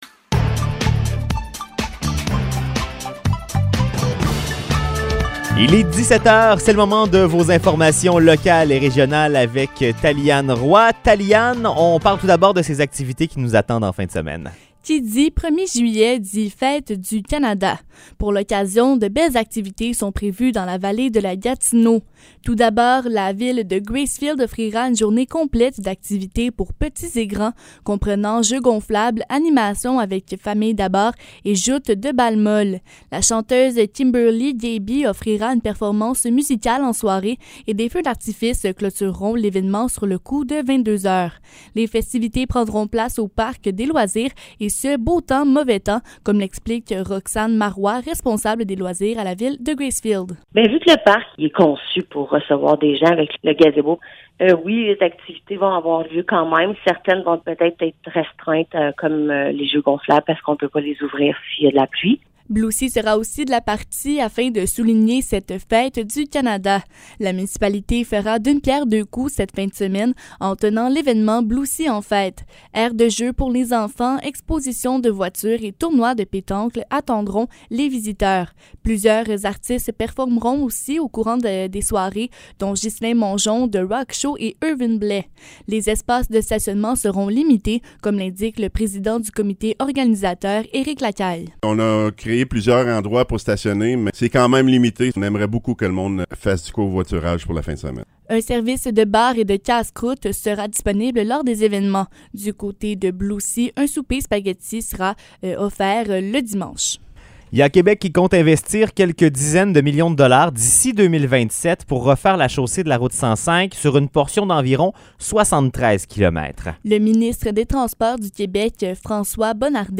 Nouvelles locales - 29 juin 2022 - 17 h